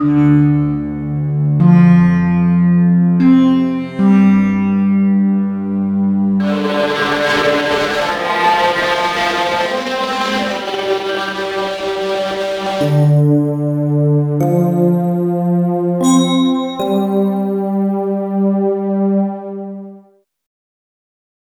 Du findest hier verschiedene Pads, fette orchestrale und sogar verzerrte Gitarren, die mit einem Bogen gespielt werden – Sigur Rós lässt grüßen.
Vom japanischem Dōjō bis hin zu einer Winterlandschaft über ein Horrorhaus scheint alles dabei. Atmosphärisch toll gelungen.